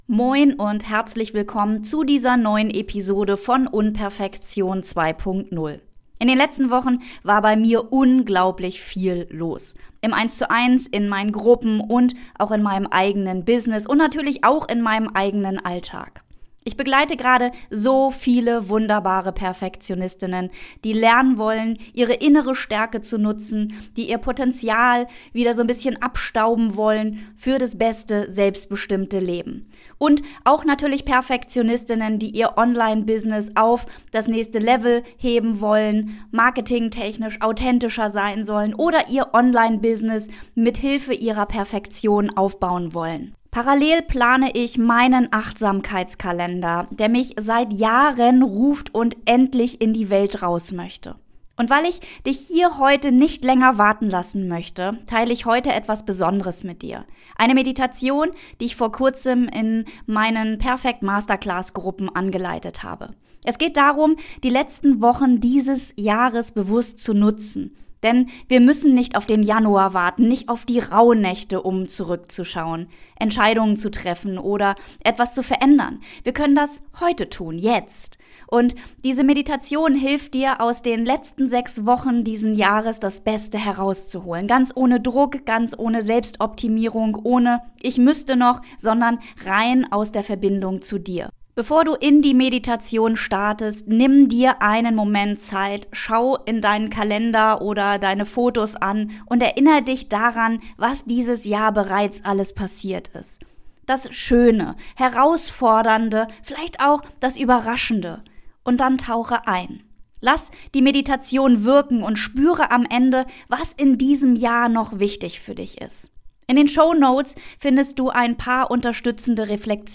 In dieser Episode nehme ich dich mit in eine geführte Meditation, die dir hilft, bewusst auf dein Jahr zurückzuschauen und klar zu erkennen, was in den letzten Wochen wirklich wichtig für dich ist.
Nach der Meditation warten fünf gezielte Reflexionsfragen auf dich, die dir Orientierung geben, Fokus schaffen und dich näher zu dir selbst bringen. Eine ruhige, klare und stärkende Folge für alle, die das Beste aus dem Jahresende machen wollen, ohne Druck, ohne Selbstoptimierung.